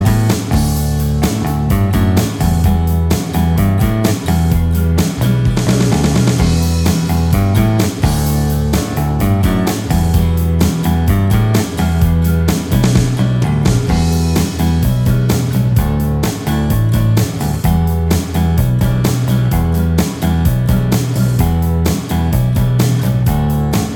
Minus Electric Guitars Indie / Alternative 3:14 Buy £1.50